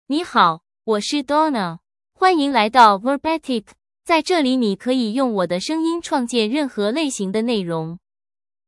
Donna — Female Chinese (Mandarin, Simplified) AI Voice | TTS, Voice Cloning & Video | Verbatik AI
DonnaFemale Chinese AI voice
Voice sample
Female